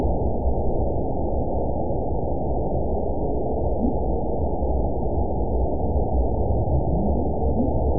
event 922028 date 12/25/24 time 20:20:10 GMT (11 months, 1 week ago) score 6.81 location TSS-AB02 detected by nrw target species NRW annotations +NRW Spectrogram: Frequency (kHz) vs. Time (s) audio not available .wav